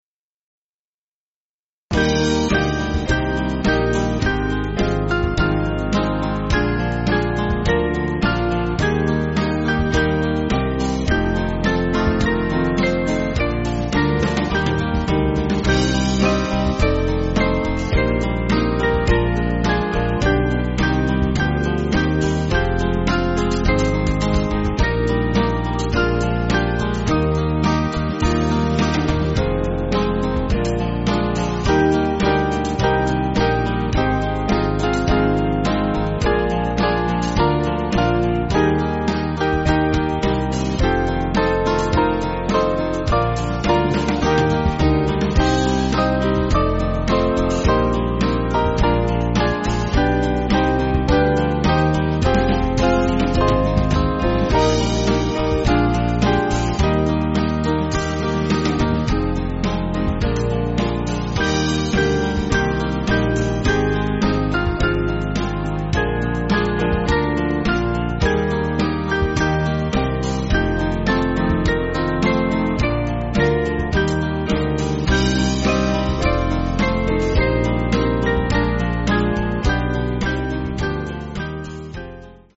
Small Band
(CM)   4/Eb